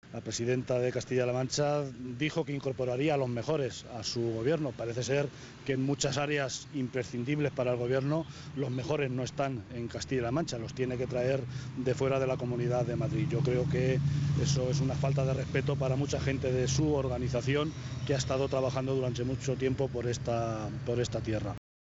José Luis Martínez Guijarro, portavoz del Grupo Parlamentario Socialista.
Cortes de audio de la rueda de prensa